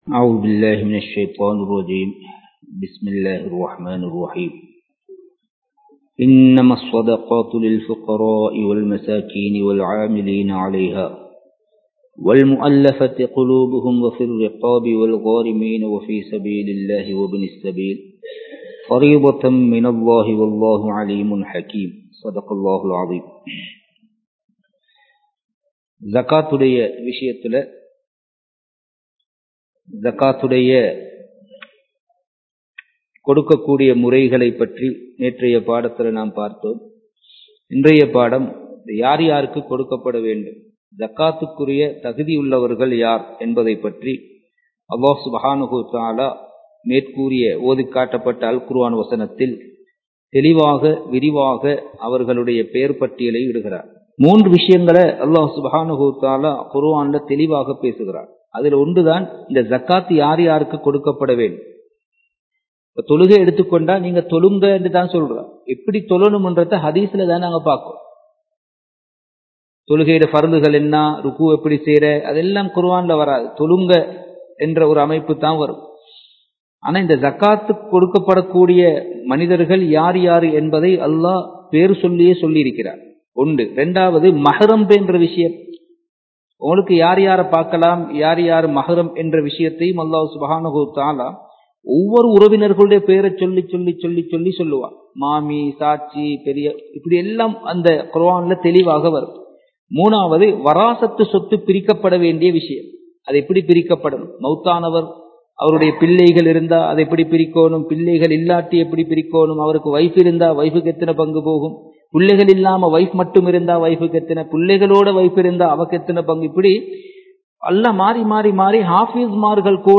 Kattukela Jumua Masjith